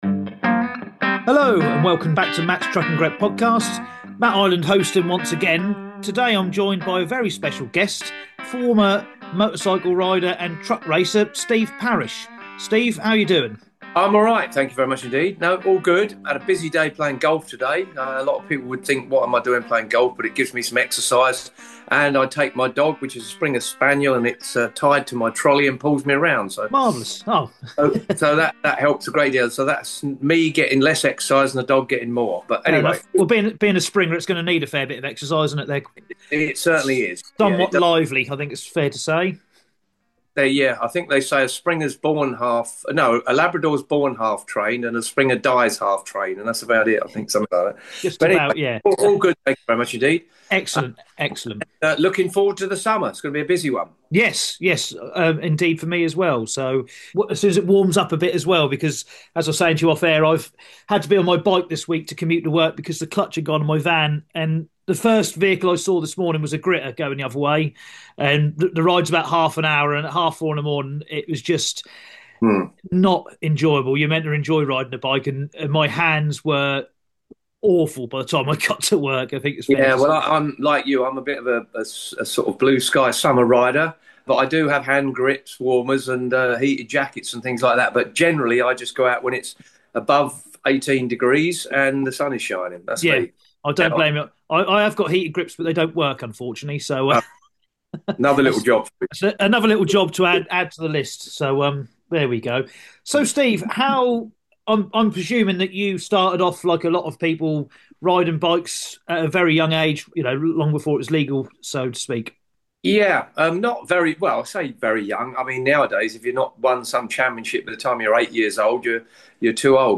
This is the podcast for truck drivers, hosted by and featuring interviews from people in the industry.